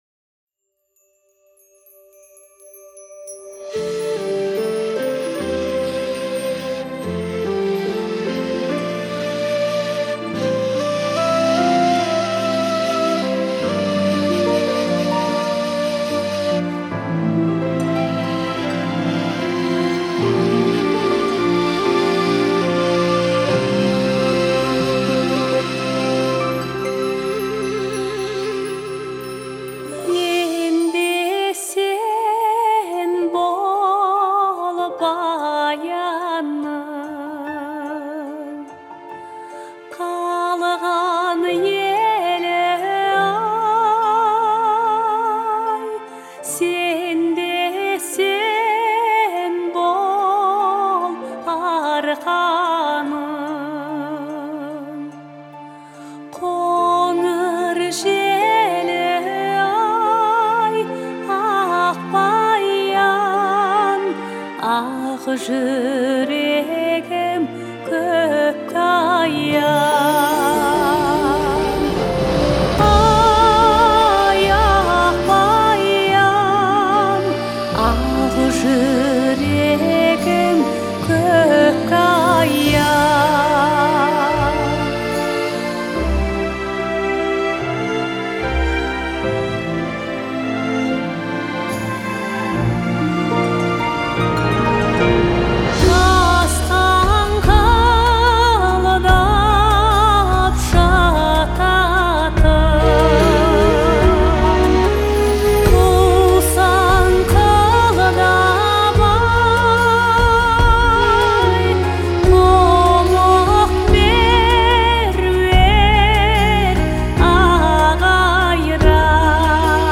её голос звучит нежно и тепло